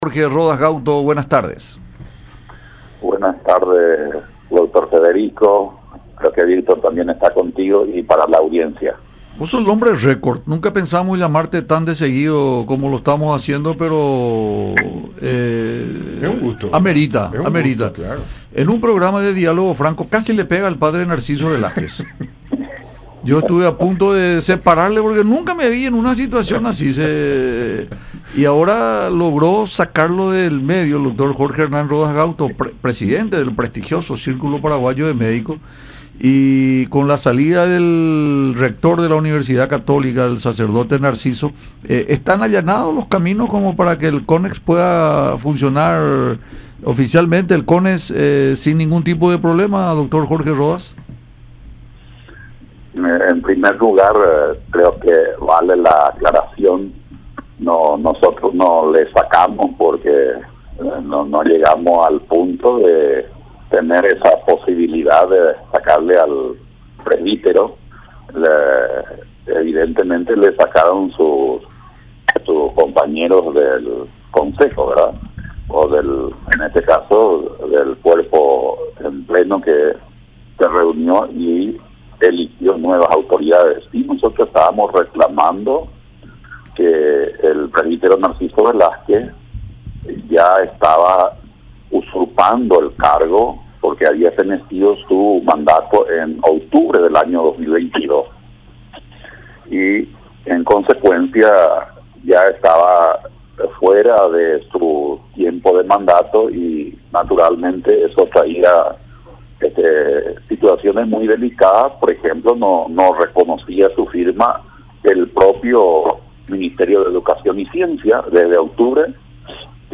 en conversación con Francamente por Unión TV y radio La Unión